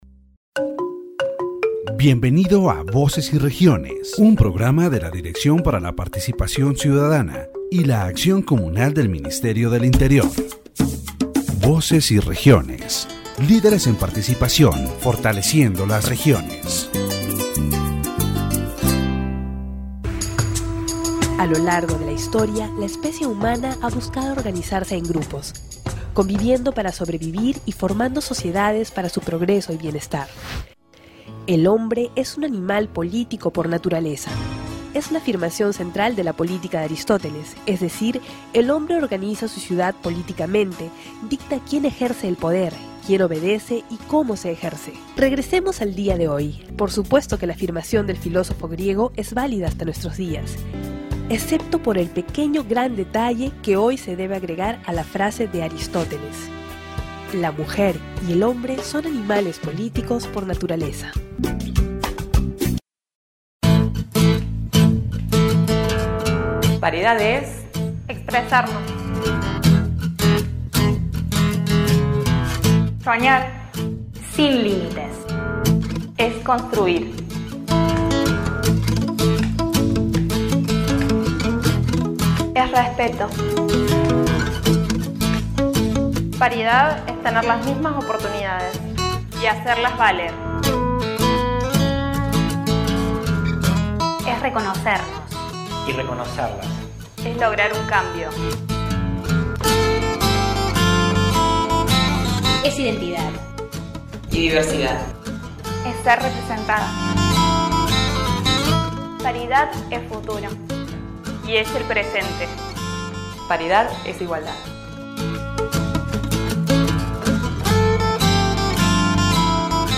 The radio program Voces y Regiones highlights the importance of gender parity in politics and the active participation of women.
In addition, the program presents testimonies about the advances and challenges of women in Colombian politics, underscoring the need for cultural and structural change to achieve true equality.